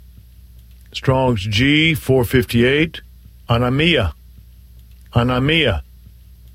Phiên âm quốc tế: ä-no-mē’-ä Phiên âm Việt: a-no-mí-a